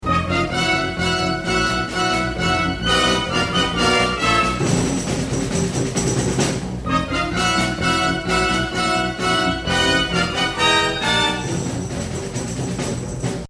1 dramatic comming music